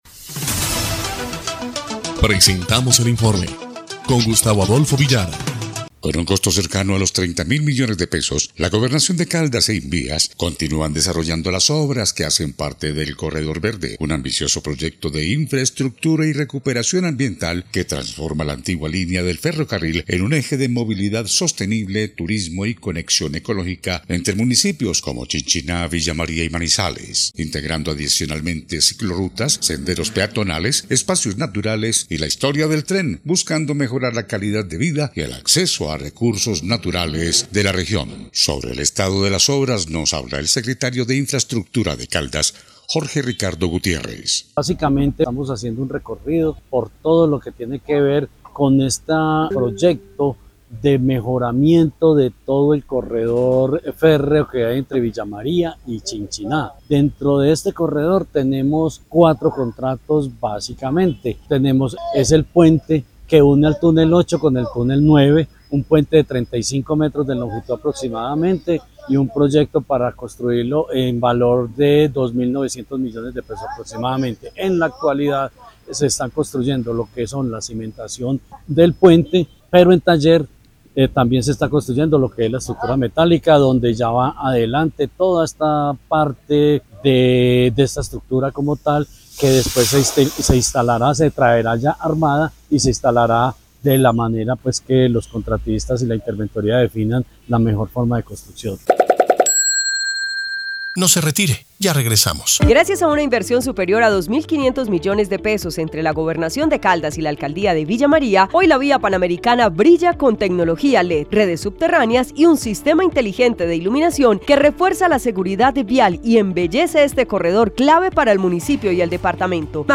EL INFORME 3° Clip de Noticias del 11 de diciembre de 2025